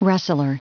Prononciation du mot rustler en anglais (fichier audio)
Prononciation du mot : rustler